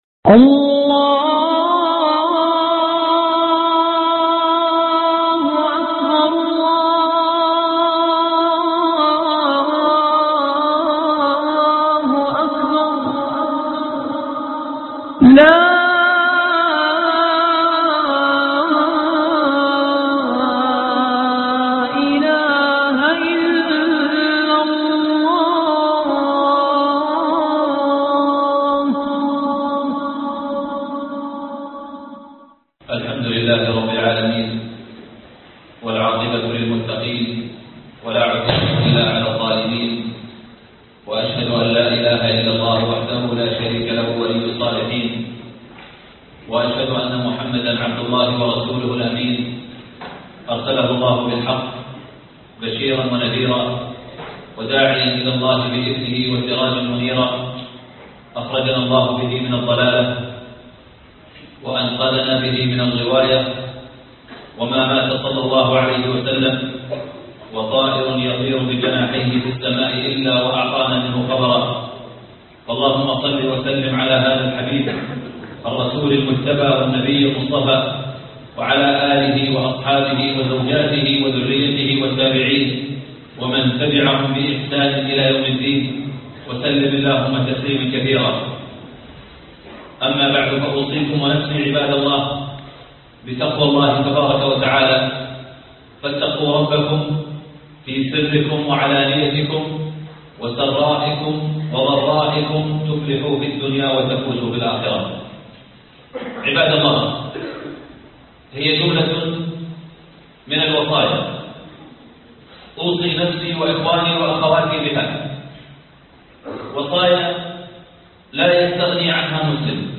وصايا الوداع (خطب الجمعة